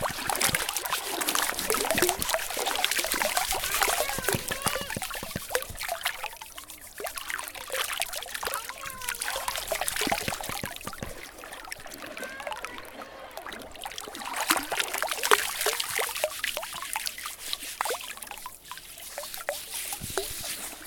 물장구.mp3